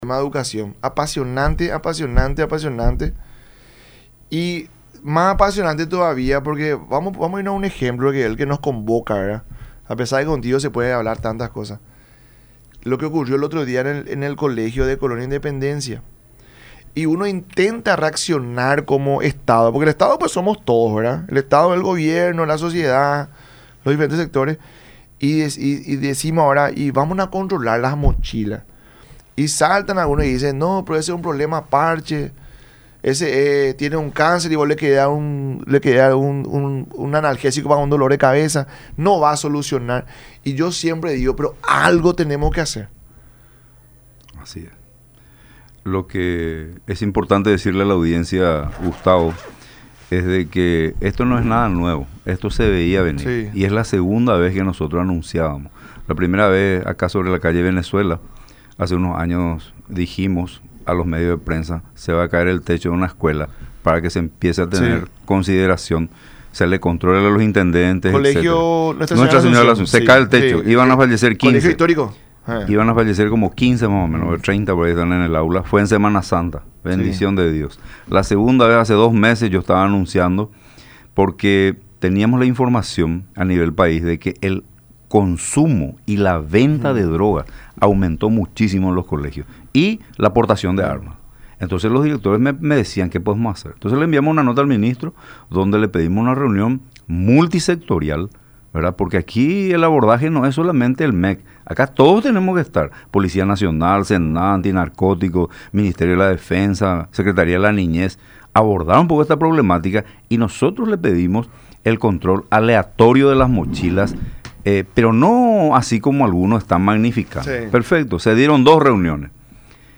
en su visita a los estudios de Unión TV y radio La Unión durante el programa La Mañana De Unión